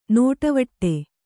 ♪ nōṭavaṭṭe